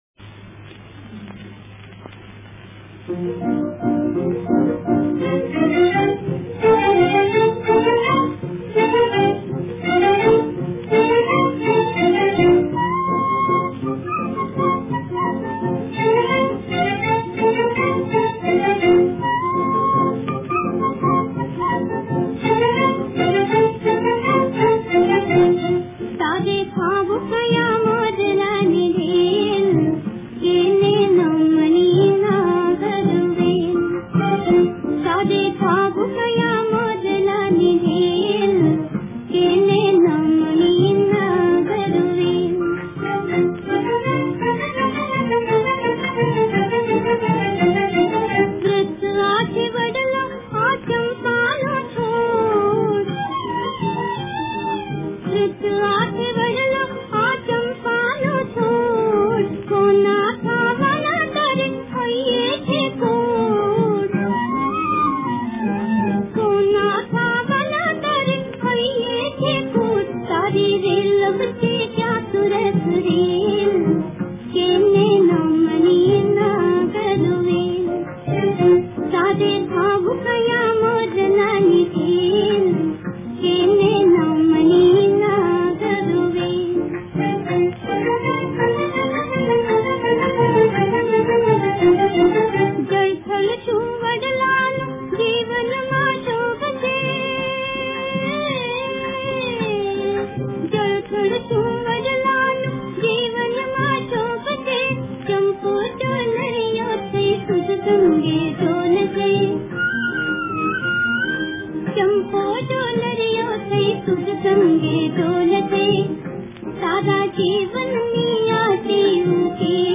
તારે થાવું કયા મોરલાની ઢેલ - Tare Thavun Kaya Moralani Dhela - Gujarati Kavita - લોક ગીત (Lok-Geet) - Gujarati World